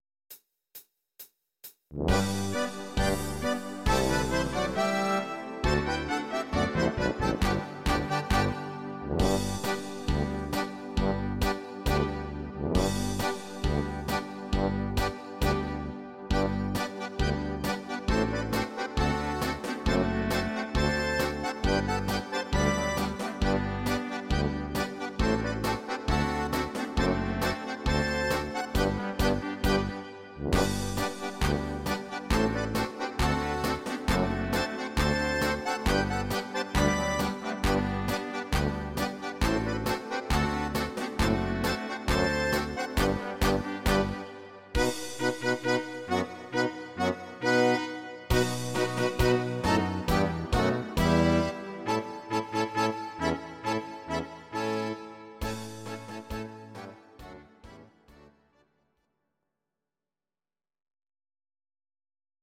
Rheinländer